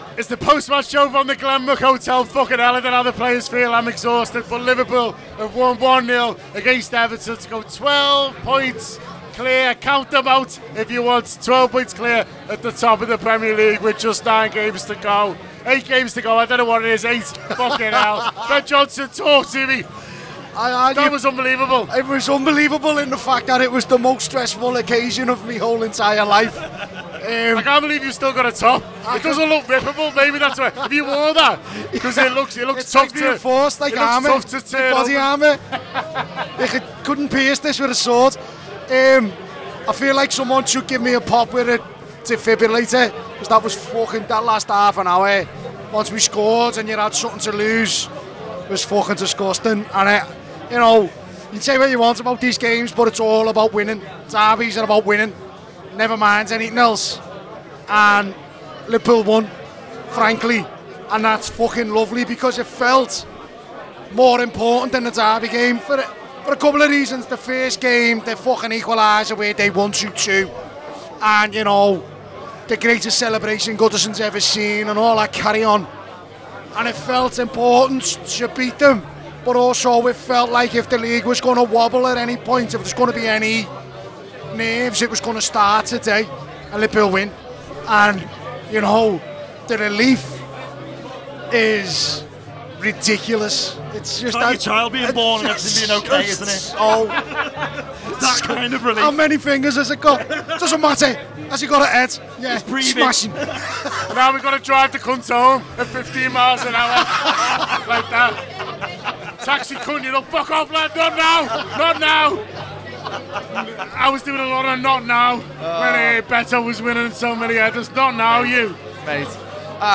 Below is a clip from the show – subscribe to The Anfield Wrap for more reaction to Liverpool 1 Everton 0